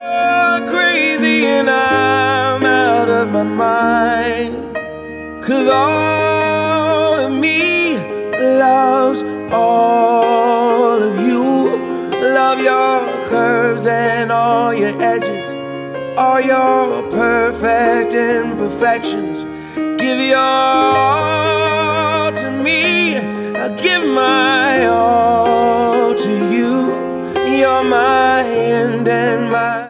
• R&B/Soul
It is a simple, modest proposal set against delicate keys.